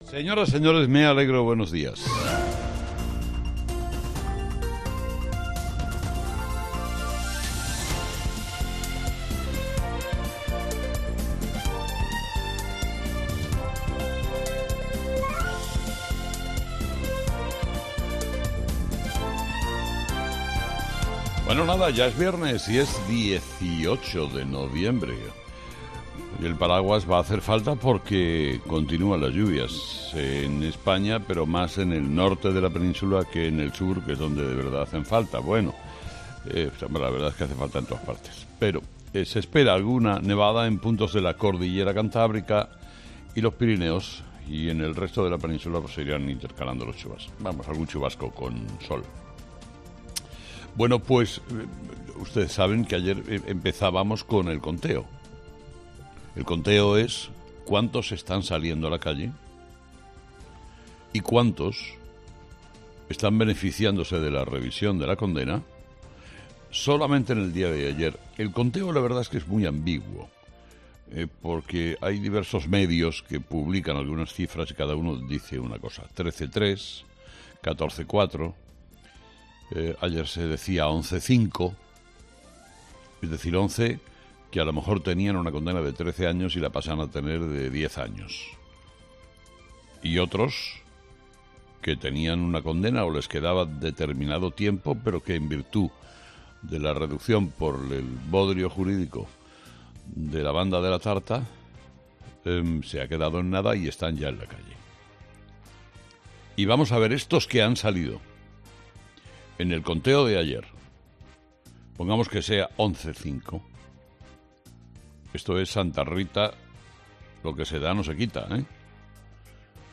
Carlos Herrera repasa los principales titulares que marcarán la actualidad de este viernes 18 de noviembre en nuestro país